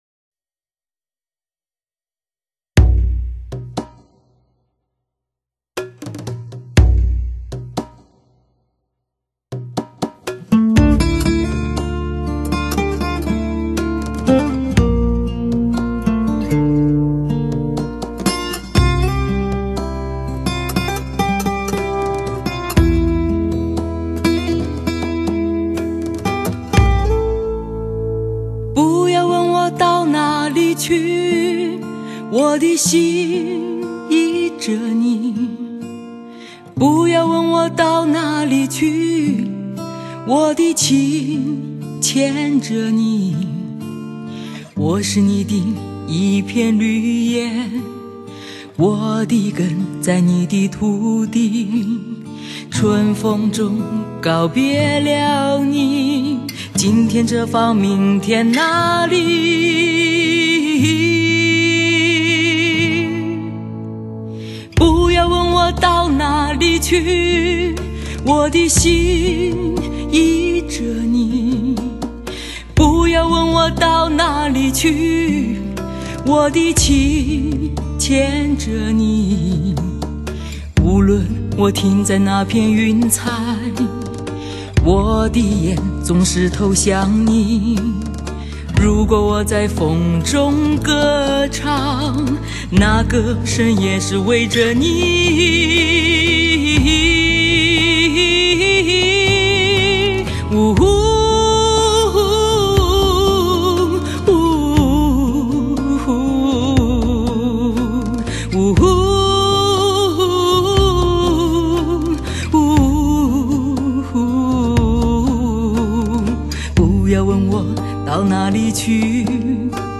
低音皇后的淳朴声色，2008动态风情力作。